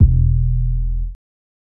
KICK_SLIT.wav